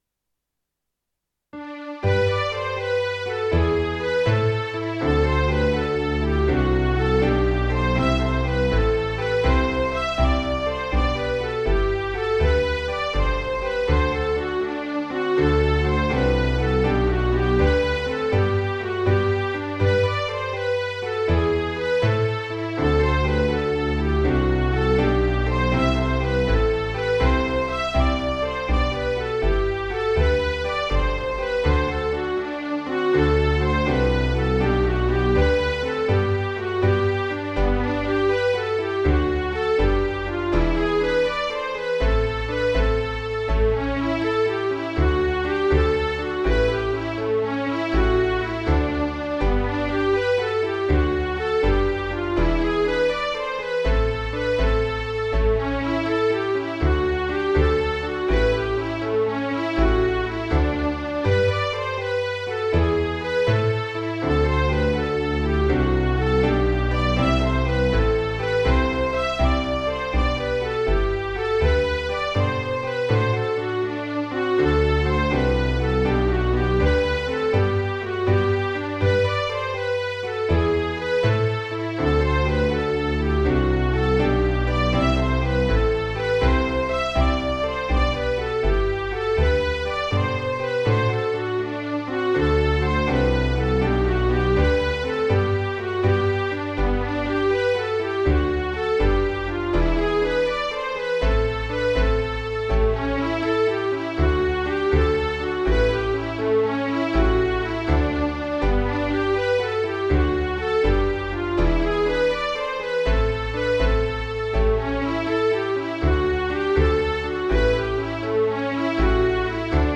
Spelmanslåt
Lyssna till datorversion